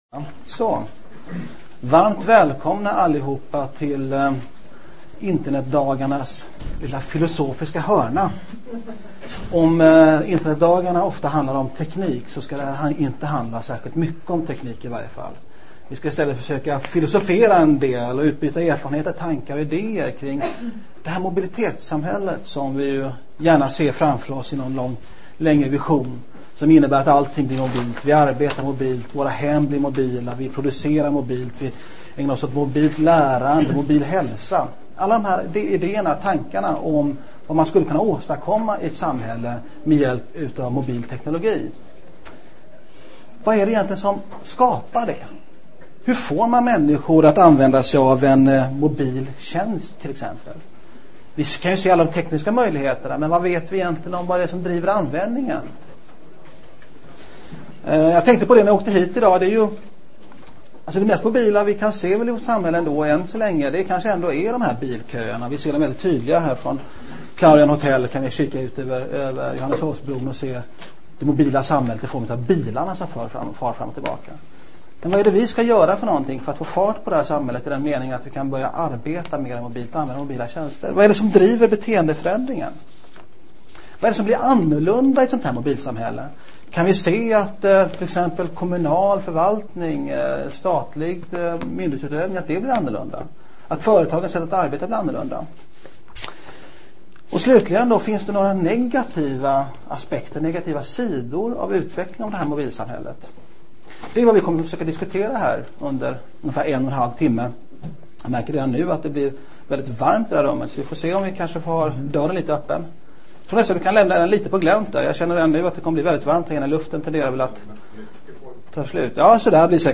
Dessa roller sm�lter i dagens samh�lle allt mer samman och mobiliteten kan underl�tta detta. I detta seminarium vill vi . med individen som utg�ngspunkt . belysa och diskutera det framtida mobila samh�llet.